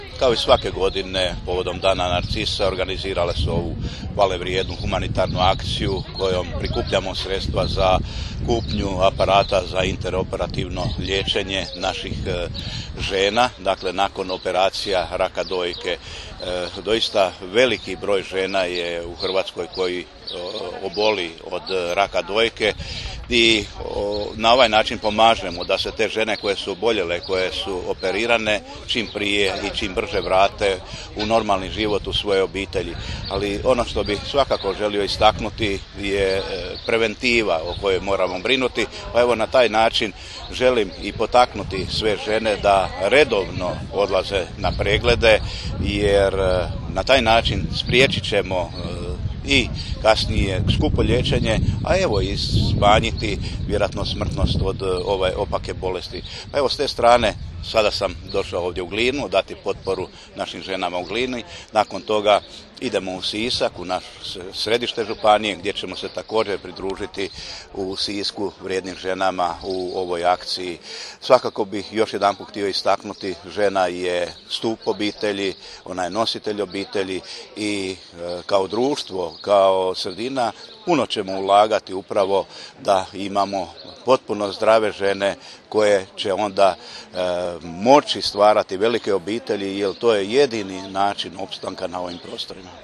Izjavu, koju je u Glini dao župan Ivo Žinić poslušajte ovdje: